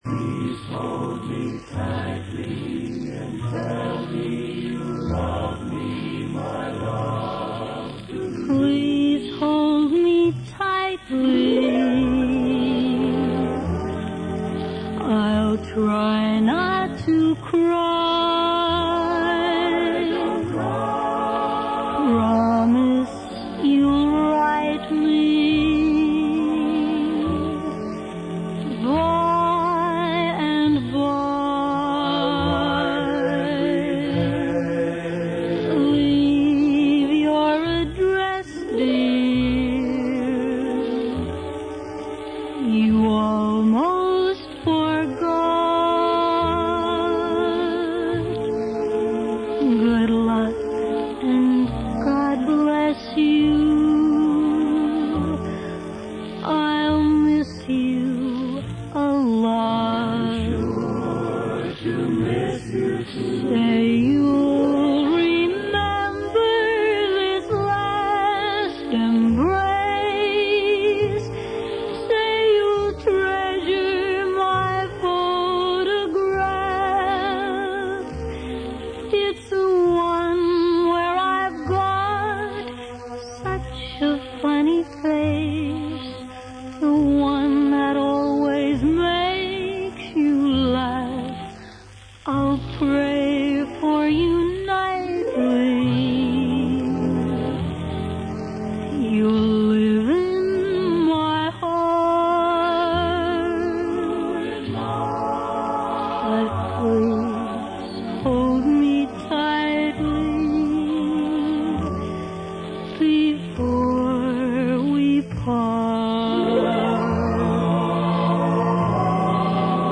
from a 78 rpm record